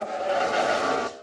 Minecraft Version Minecraft Version latest Latest Release | Latest Snapshot latest / assets / minecraft / sounds / mob / horse / skeleton / idle1.ogg Compare With Compare With Latest Release | Latest Snapshot